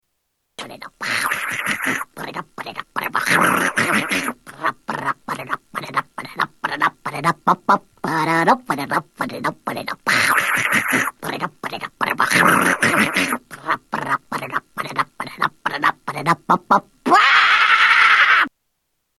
Horse noises